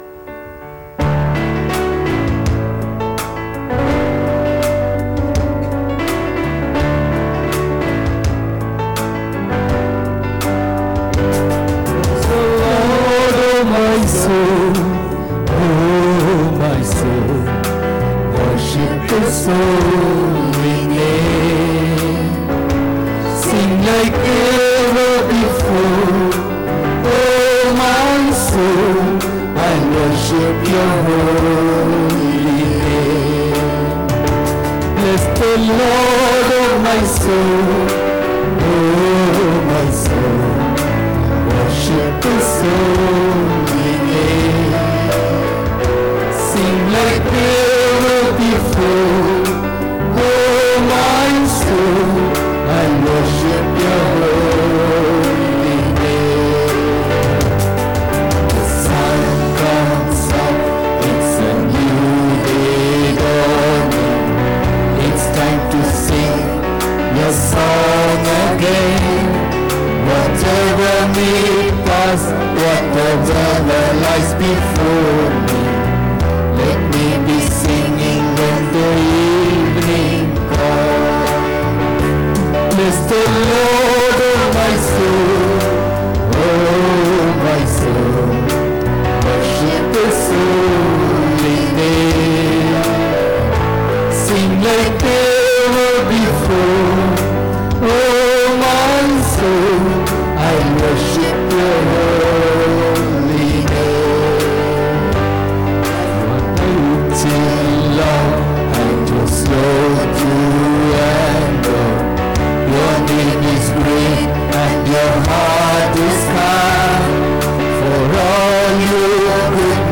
19 Nov 2023 Sunday Morning Service – Christ King Faith Mission